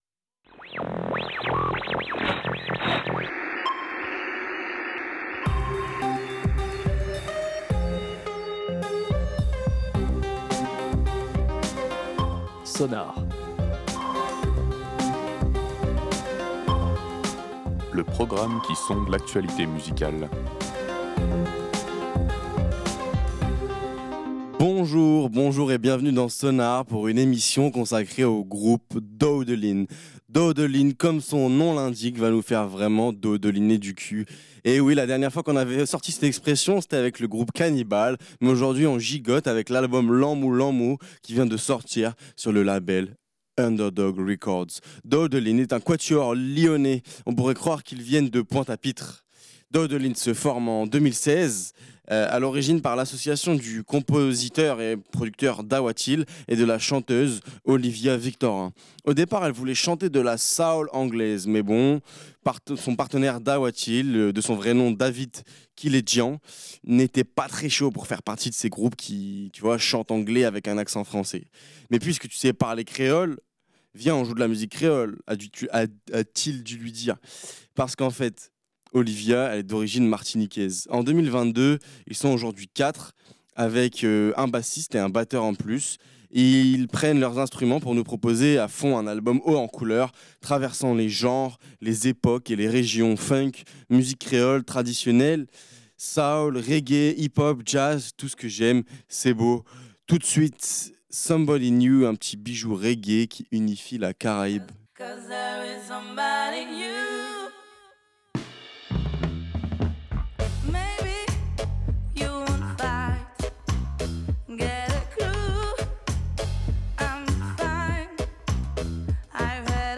Funk, musique créole traditionnelle, reggae, hip-hop, jazz, tout les genres se mélangent dans les neuf titres du projet Lanmou Lanmou.